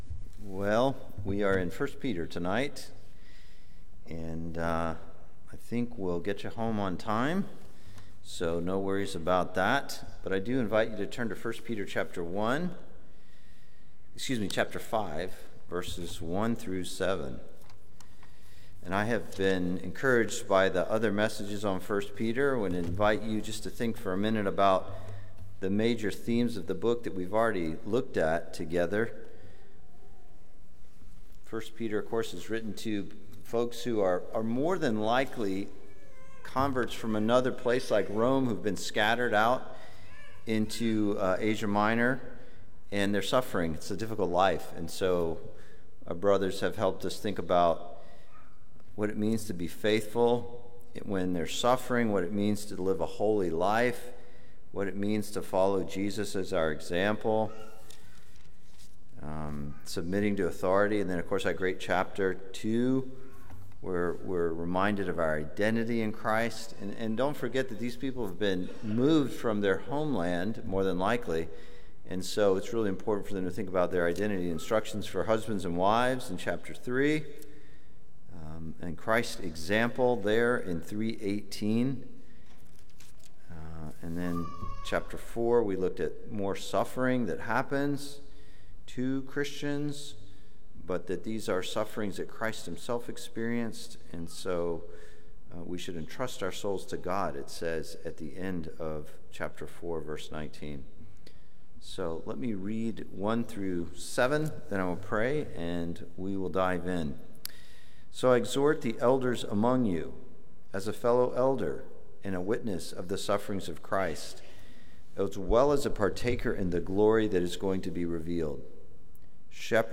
Similar to High Pointe Baptist Church Sermons